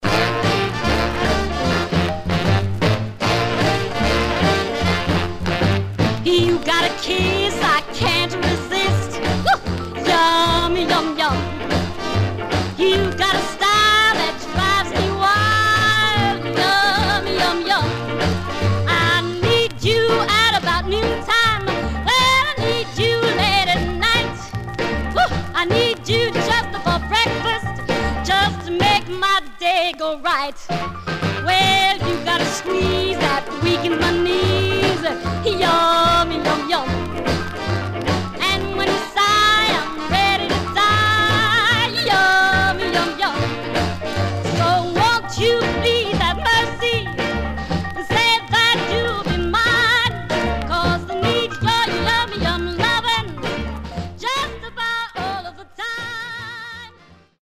Vocals
Condition Surface noise/wear Stereo/mono Mono
Rythm and Blues